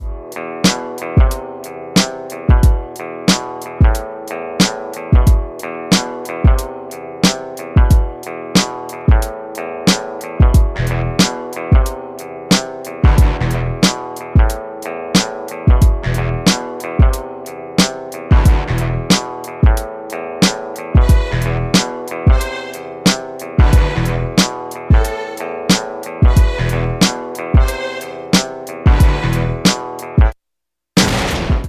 • Качество: 128, Stereo
выстрел